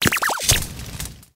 Grito de Charcadet.ogg
Grito_de_Charcadet.ogg.mp3